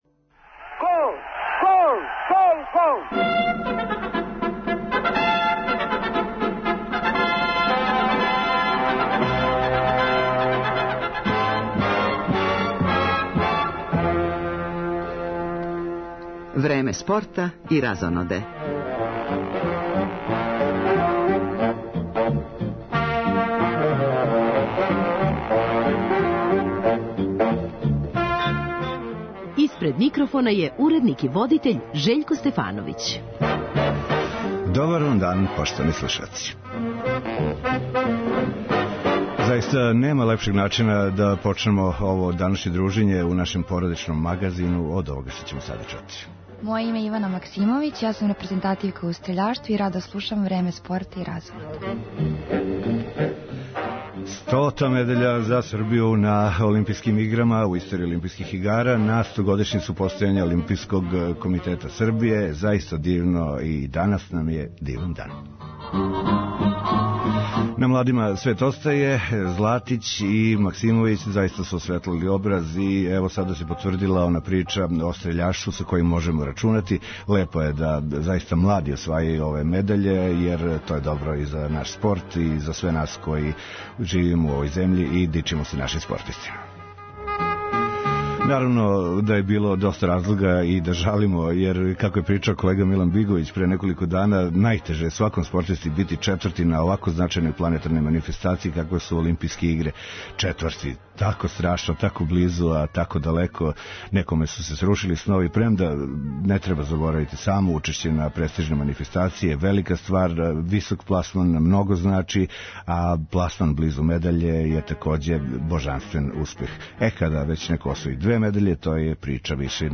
Као најзначајни планетарни догађај, ова манифестација заузима централно место у спортском блоку суботњег породичног магазина Радио Београда 1.